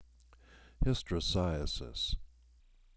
This plays the word pronounced out loud.